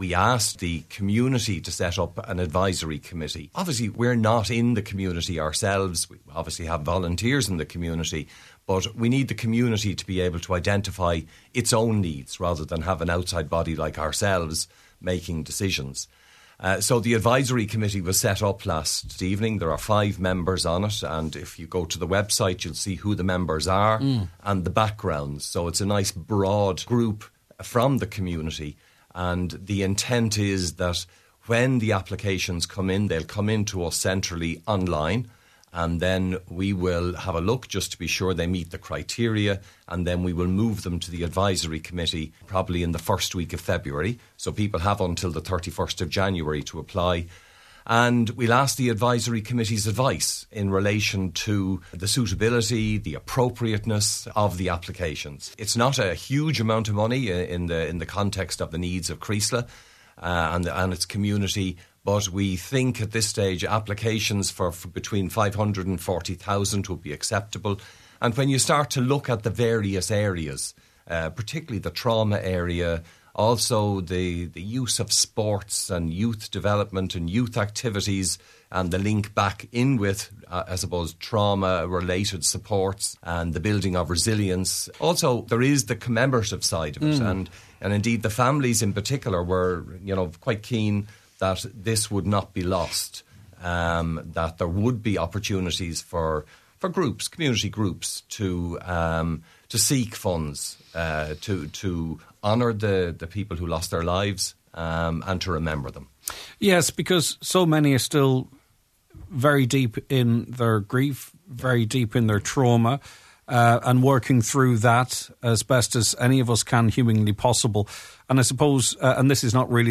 spoke on today’s Nine til Noon Show after two meetings in Creeslough last night.